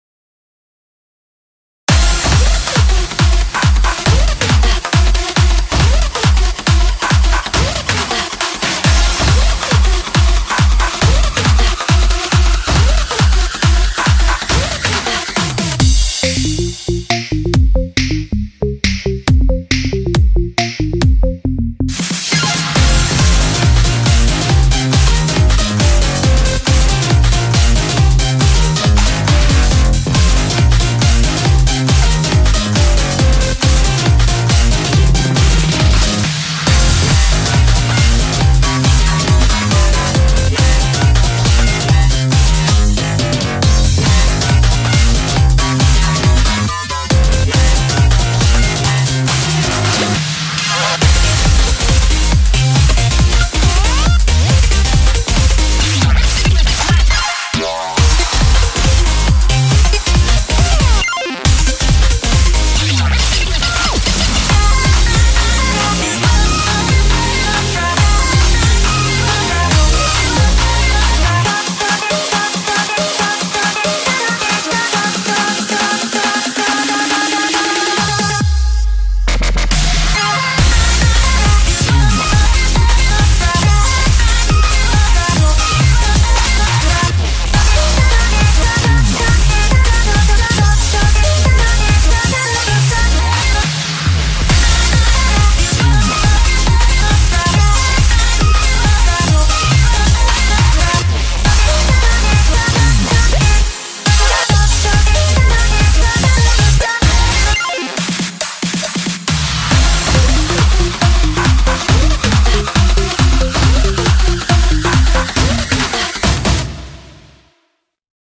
BPM138
Audio QualityPerfect (Low Quality)